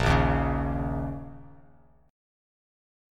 A#mbb5 chord